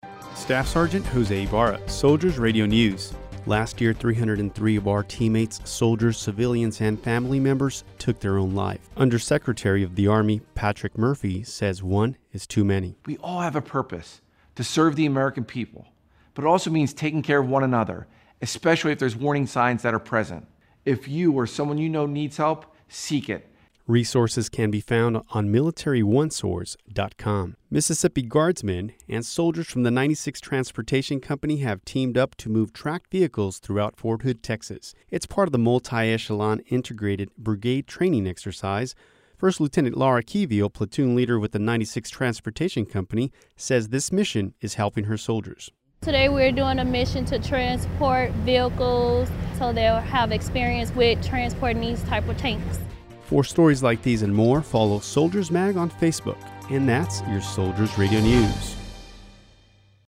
Soldiers Radio News
Suicide awareness message from Under Secretary of the Army Patrick Murphy and Mississippi Guardsmen team up with 96th Transportation Company during the Mutli-Echelon Integrated Brigade Training Exercise at Fort Hood Texas